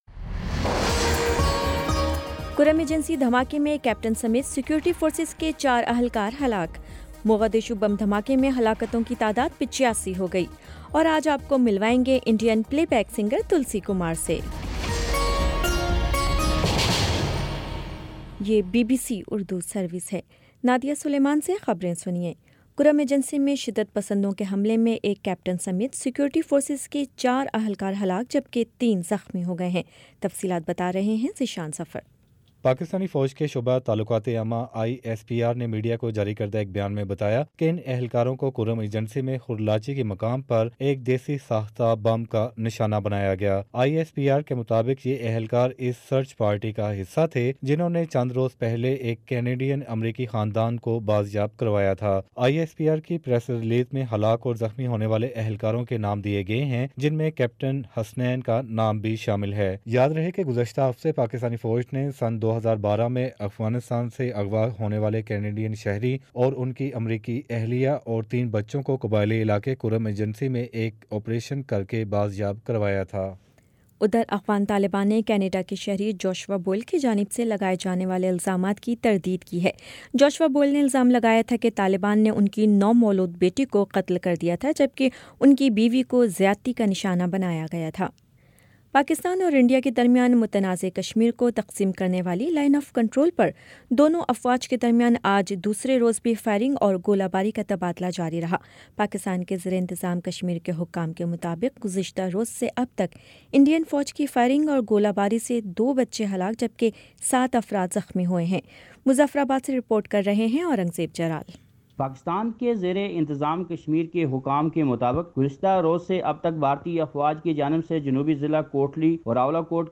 اکتوبر 15 : شام سات بجے کا نیوز بُلیٹن